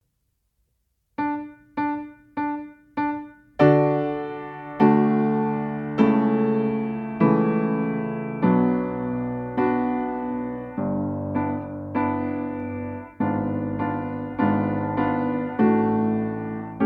Nagranie dokonane na pianinie Yamaha P2, strój 440Hz
piano